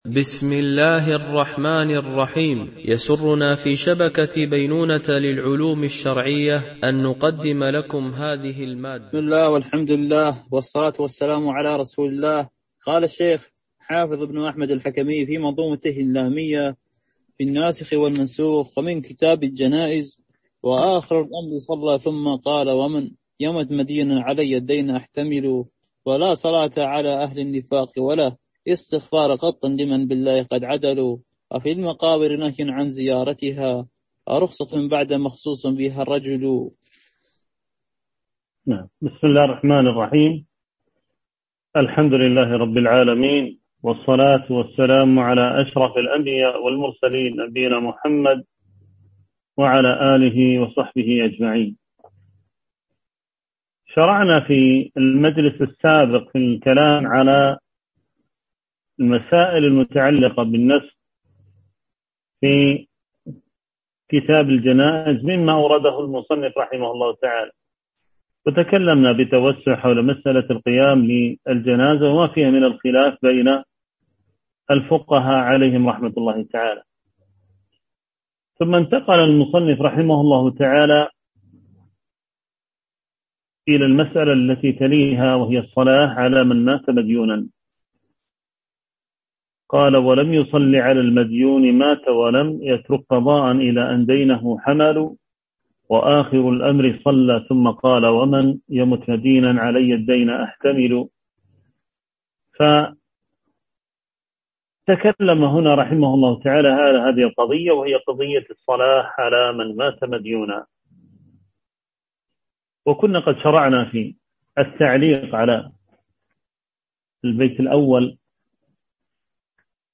شرح المنظومة اللامية في الناسخ والمنسوخ - الدرس 14